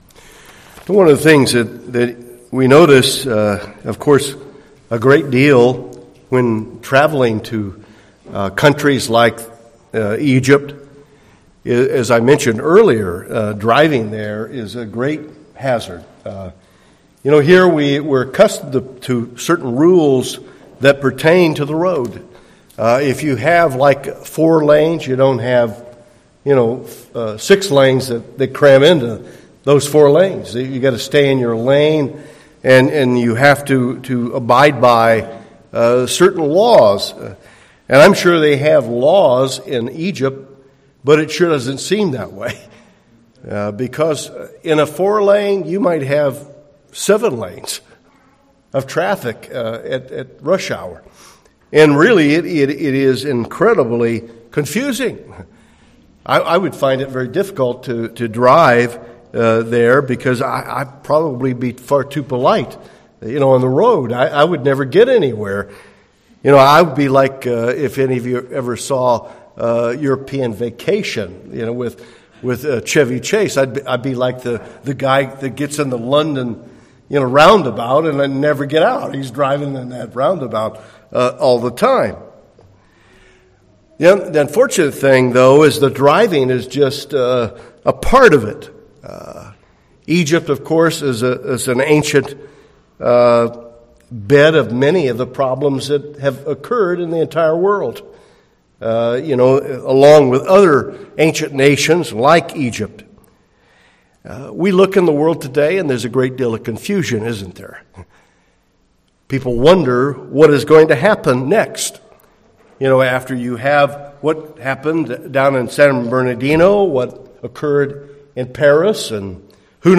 This sermon describes what Babylon represents in the Bible (historically and prophetically).